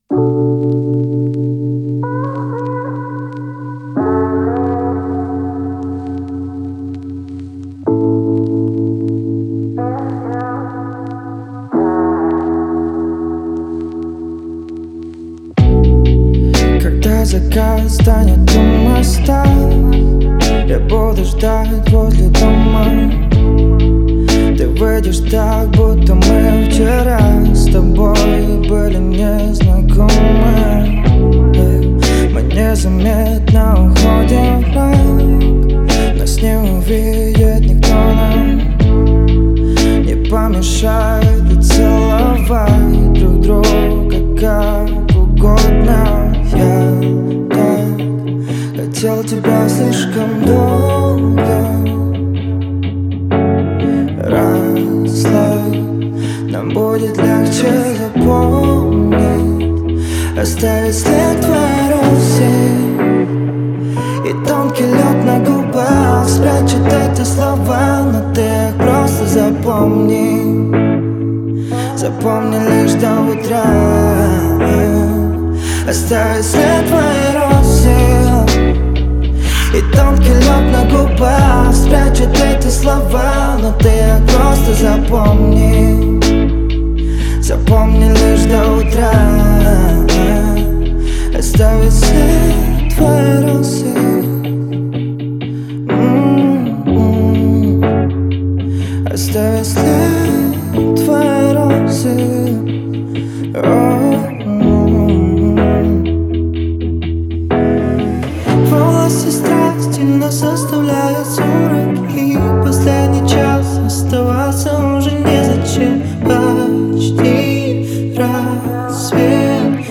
это проникновенная песня в жанре поп с элементами R&B